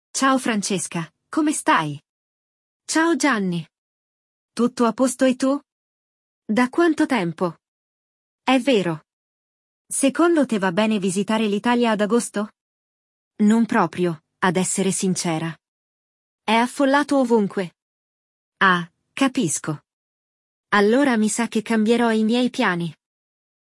Neste episódio, você vai escutar uma conversa entre amigos que não se falaram por um tempo.